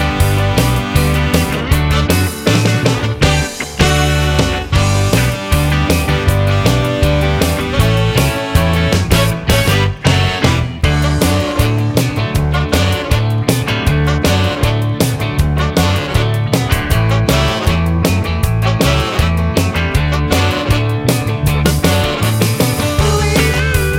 Two Semitones Down Rock 'n' Roll 2:53 Buy £1.50